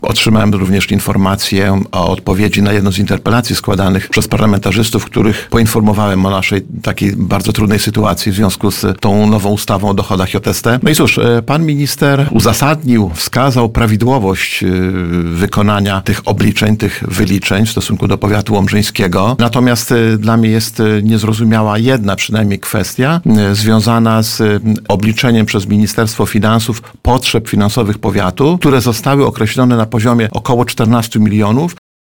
Starosta Lech Szabłowski mówił w Radiu Nadzieja, że odpowiedź z ministerstwa otrzymał w ubiegłym tygodniu.